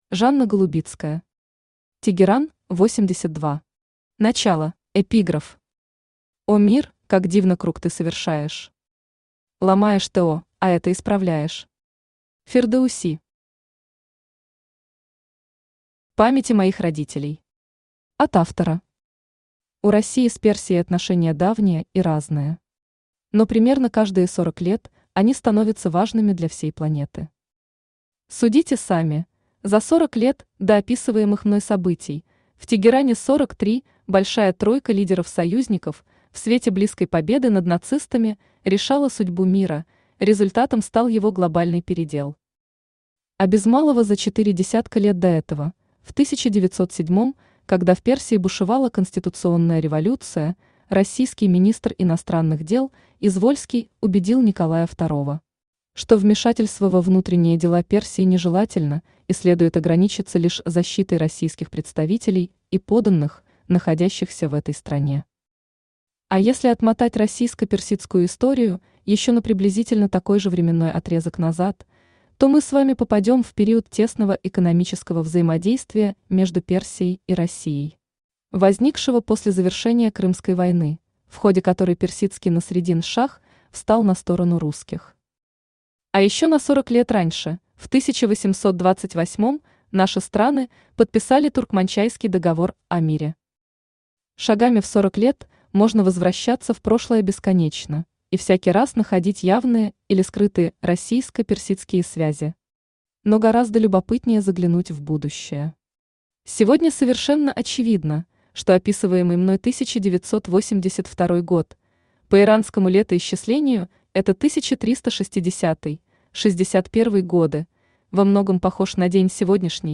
Начало Автор Жанна Голубицкая Читает аудиокнигу Авточтец ЛитРес.